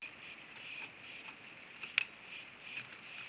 羽根を震わすとともにビブラートのかかった声を出す